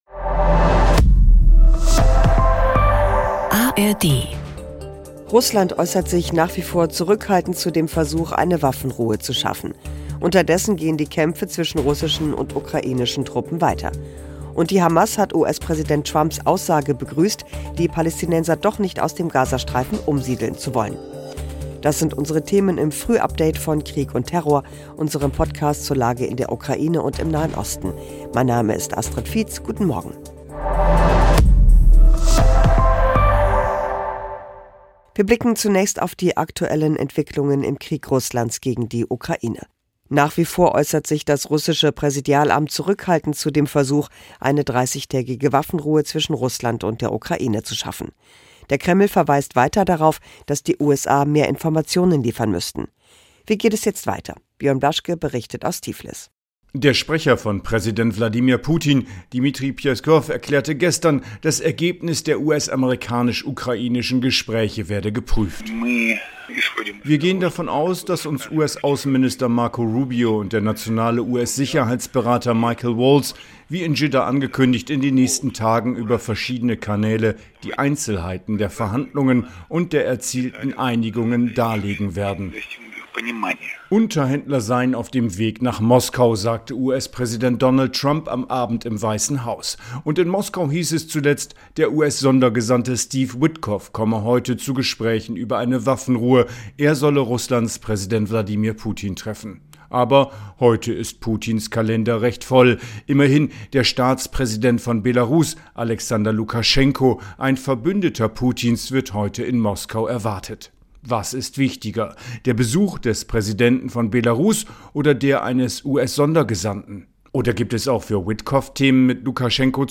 Nachrichten - 13.03.2025